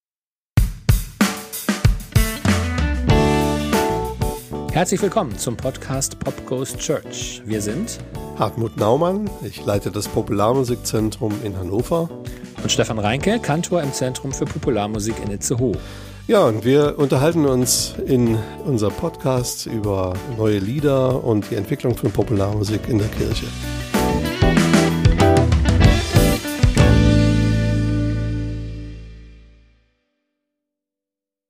Unser Podcast startet...mit einem Jingle